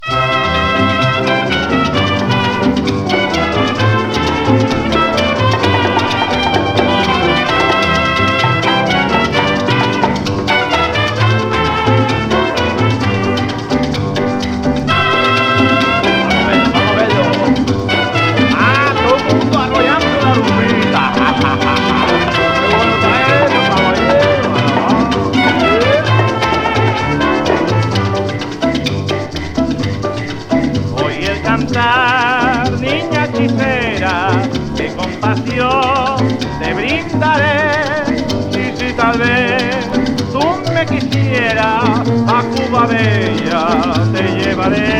World, Latin, Rumba, Cubano　Germany　12inchレコード　33rpm　Mono